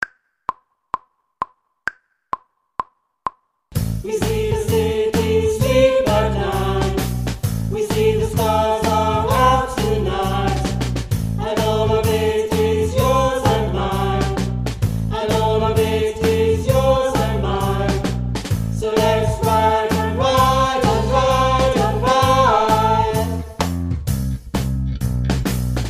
Passenger vocals section 6